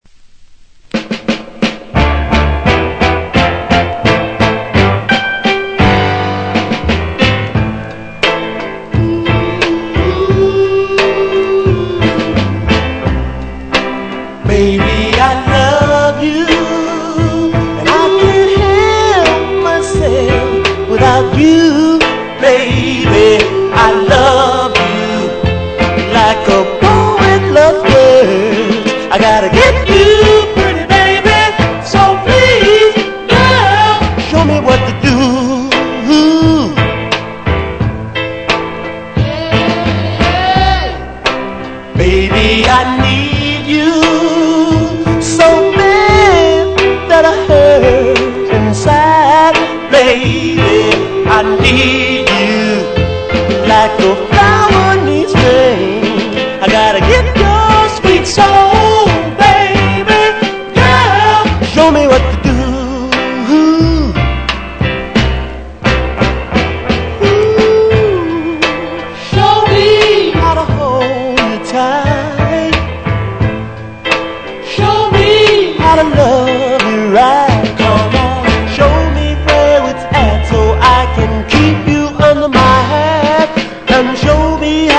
Genre: RARE SOUL